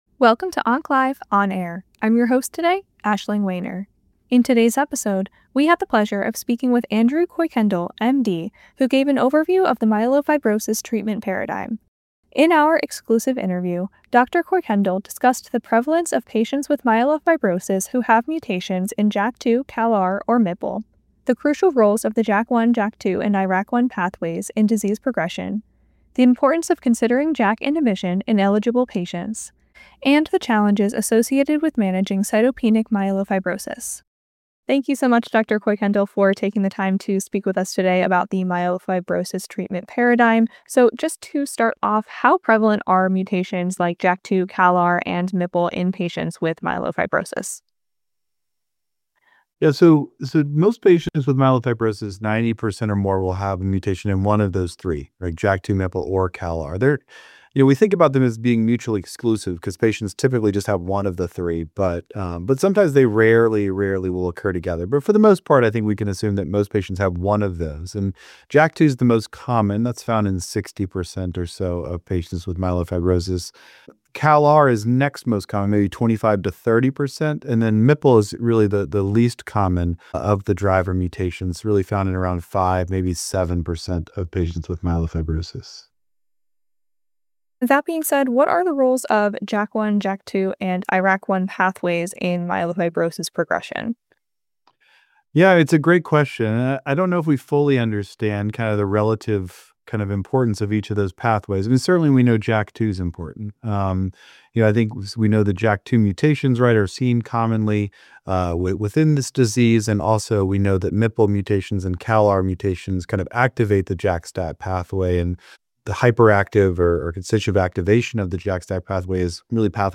In our exclusive interview